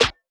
SouthSide Snare (41).wav